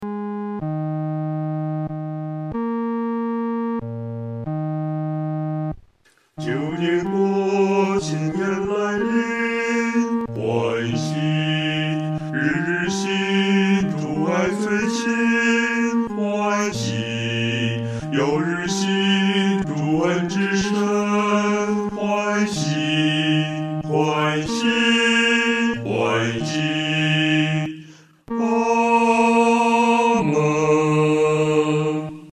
男低
本首圣诗由网上圣诗班录制